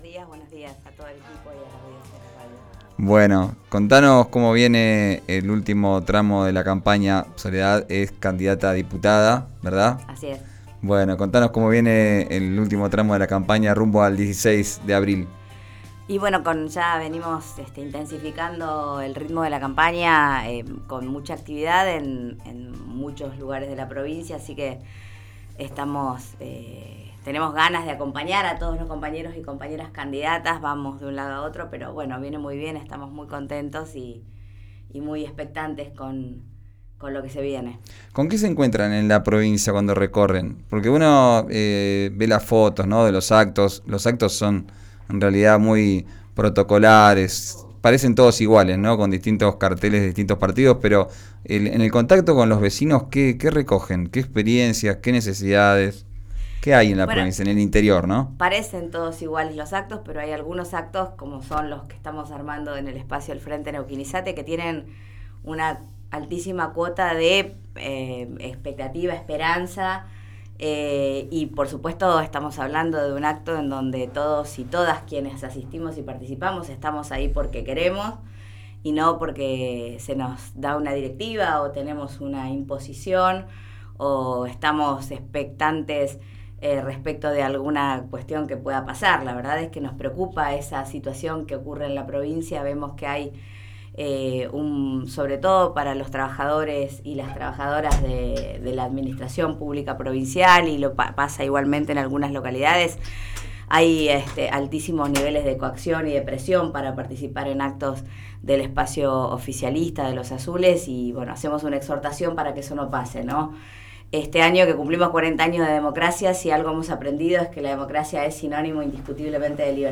Escuchá la entrevista en 'Arranquemos', por RÍO NEGRO RADIO.
Soledad Martínez, candidata a renovar su banca en la Legislatura de Neuquén por el Frente Neuquinizate, visitó el estudio de RÍO NEGRO RADIO.